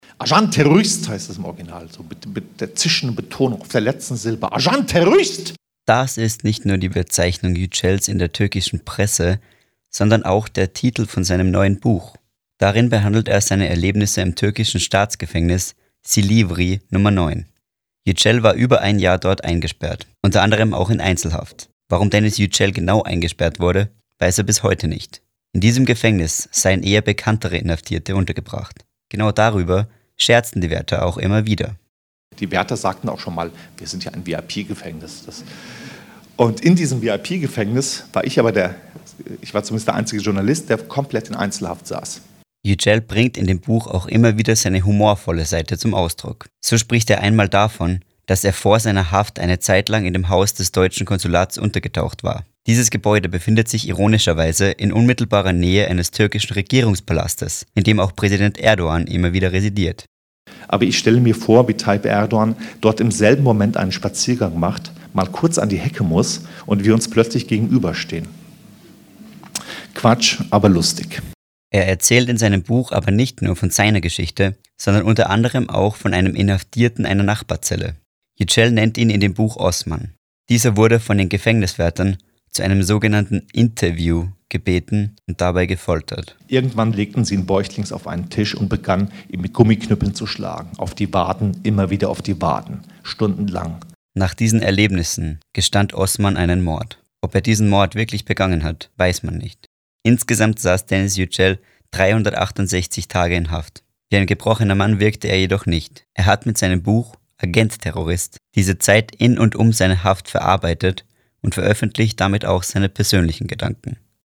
Ein Fazit zur Lesung am 10. Oktober 2019 im Rostocker Literaturhaus: Während Yücel auf eine sehr bodenständige und an einigen Stellen auch auf humorvolle Art seine persönlichen Erfahrungen sowie die großen Zusammenhänge in der türkischen Politik schilderte, lauschte das Publikum gebannt den Erzählungen des Welt-Reporters und schmunzelte und lachte bei seinen witzigen Bemerkungen.